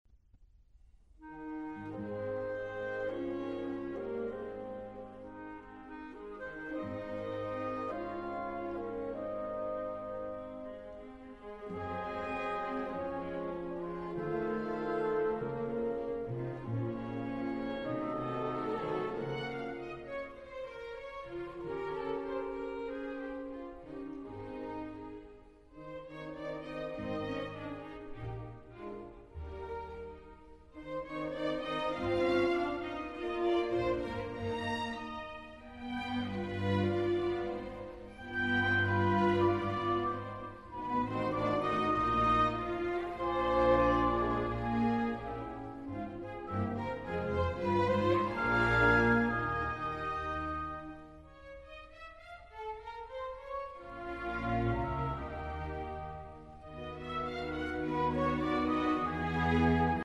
Robert Schumann - Symphony No. 3 in E flat major, Op. 97 "Rhenish" - 3. Nicht schnell